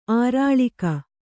♪ ārāḷika